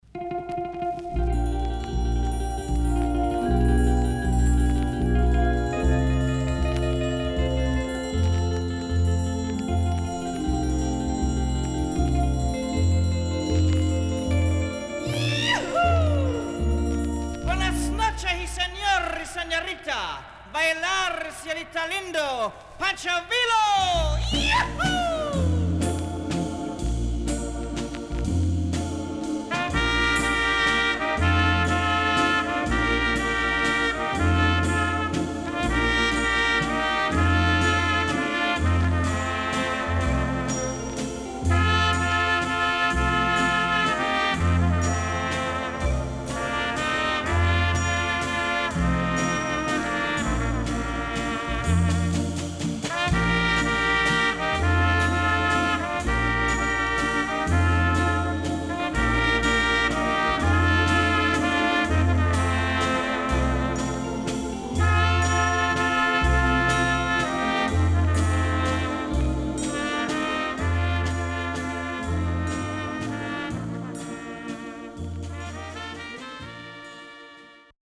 Caribbean Inst LP
SIDE A:少しノイズ入りますが良好です。